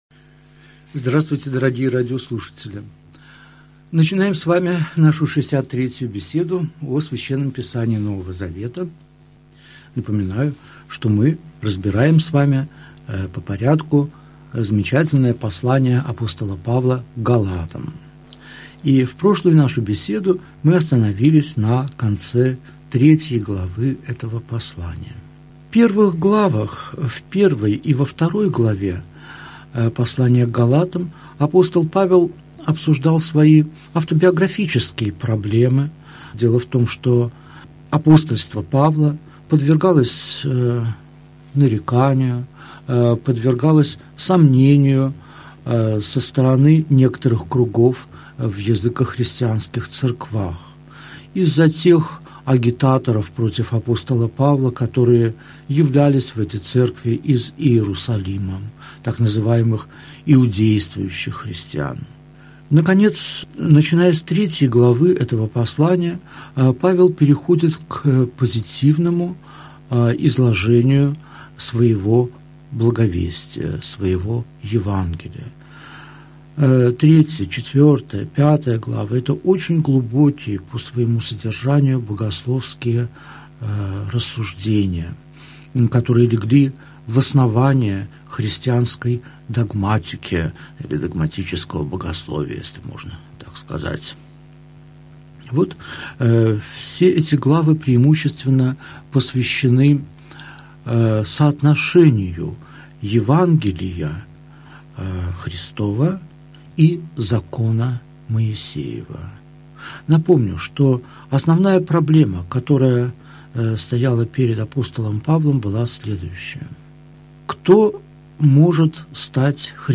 Аудиокнига Беседа 63. Послание к Галатам. Глава 4, стихи 1 – 11 | Библиотека аудиокниг